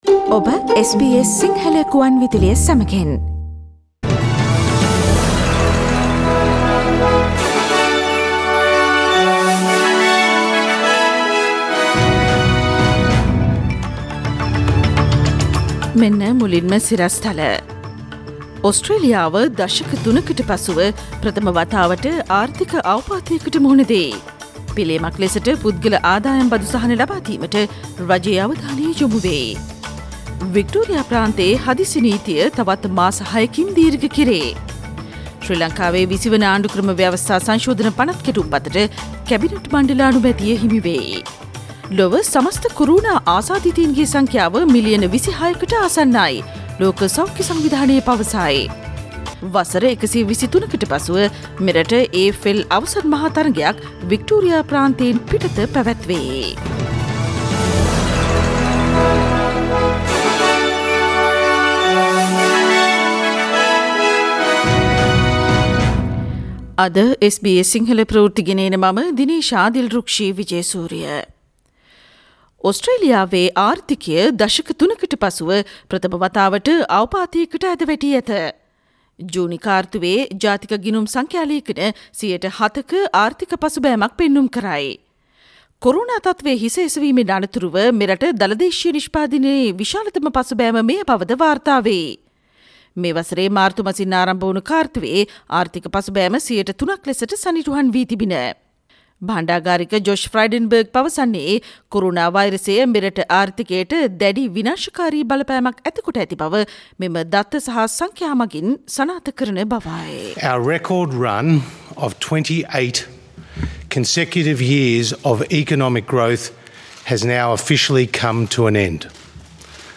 Daily News bulletin of SBS Sinhala Service: Thursday 3 September 2020
Today’s news bulletin of SBS Sinhala radio – Thursday 3 September 2020.